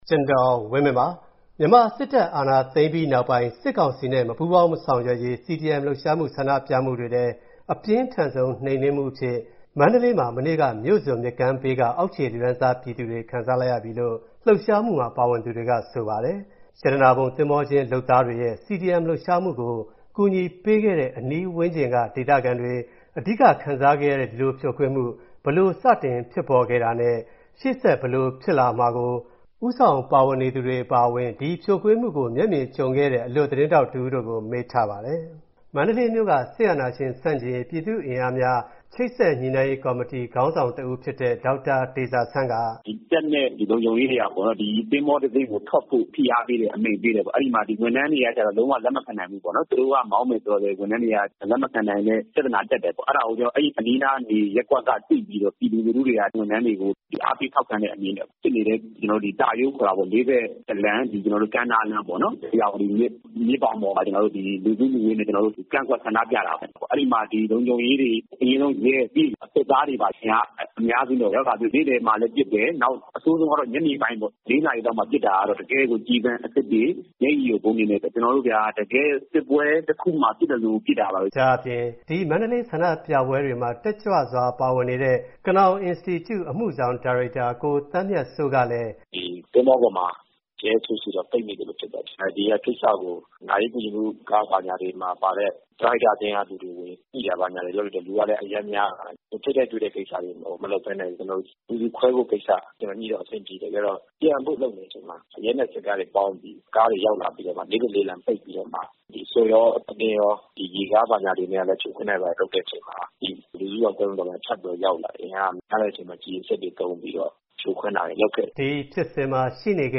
မန္တလေး အကြမ်းဖက်ဖြိုခွင်းမှုအပေါ် မျက်မြင်သက်သေတွေရဲ့ ပြောကြားချက်